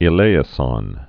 (ĭ-lāĭ-sŏn, -sən)